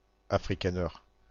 Prononciation
IPA: /a.fʁi.ka.nɛʁ/